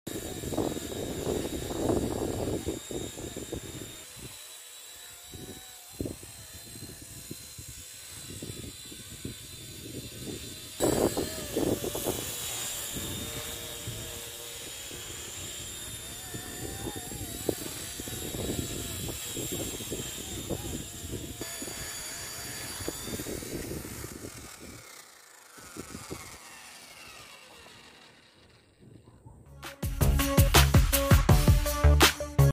DIY Remote Control Helicopter 🚁 sound effects free download